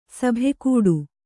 ♪ sabhe kūḍu